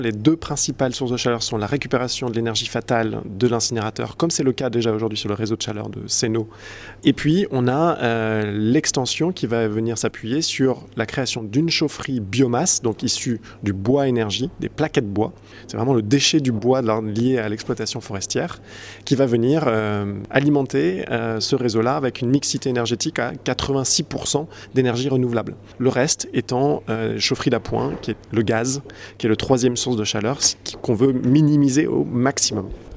Benjamin Marias, maire adjoint au développement durable à Annecy nous l'explique :